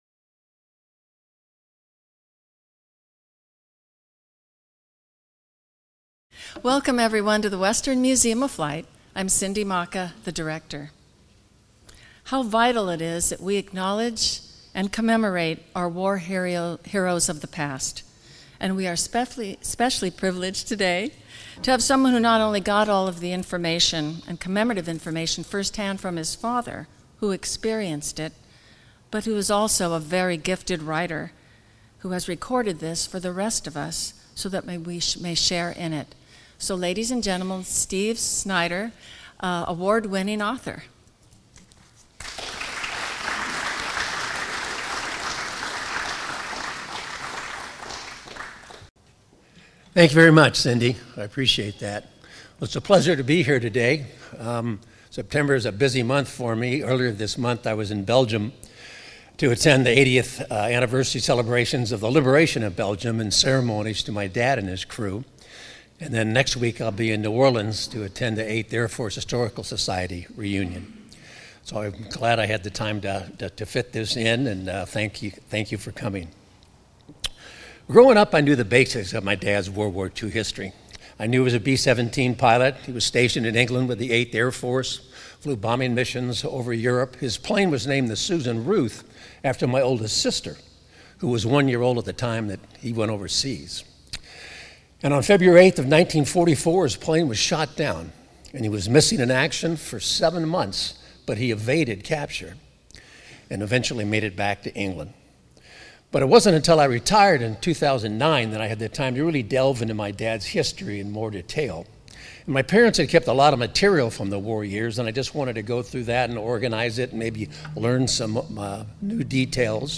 Presentation at the Western Museum of Flight